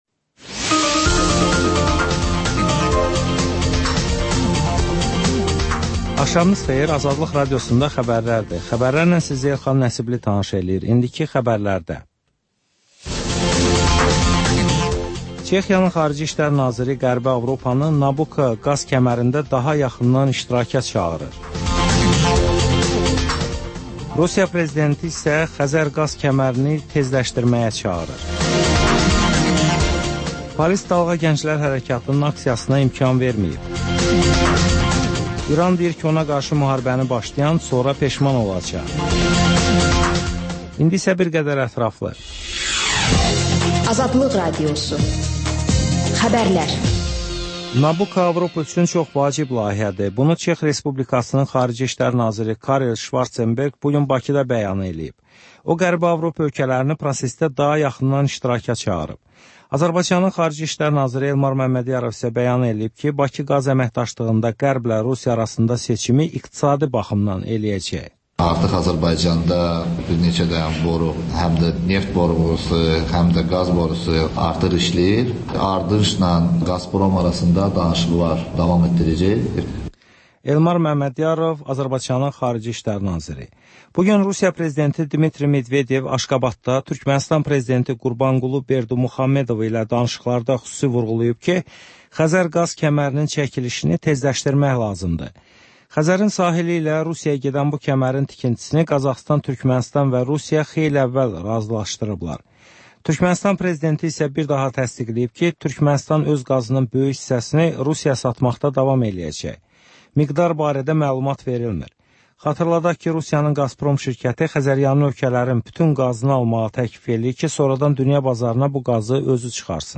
Xəbərlər, müsahibələr, hadisələrin müzakirəsi, təhlillər, sonda isə HƏMYERLİ rubrikası: Xaricdə yaşayan azərbaycanlıların həyatı